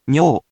We’re going to show you the character(s), then you you can click the play button to hear QUIZBO™ sound it out for you.
In romaji, 「にょ」 is transliterated as 「nyo」which sounds sort of like 「nyohh」.